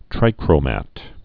(trīkrō-măt)